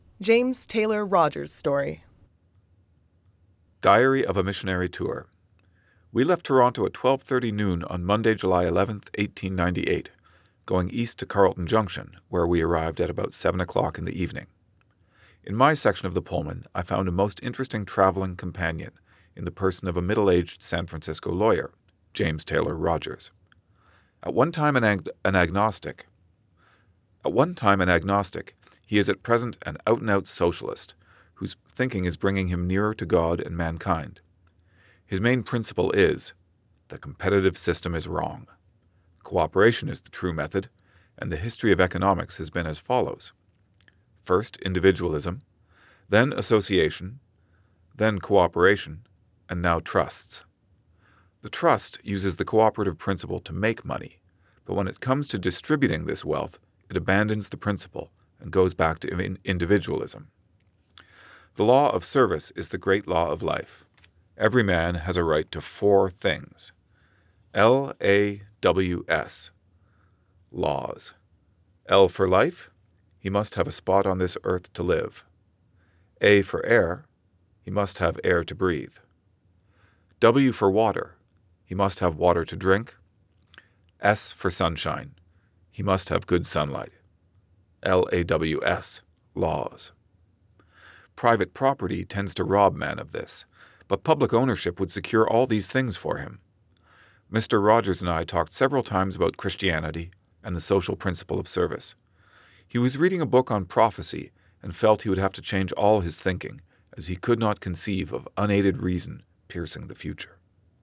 Click on the “play” button to hear the diary episode read aloud, and click on the green tab 1 to learn more about a word or phrase.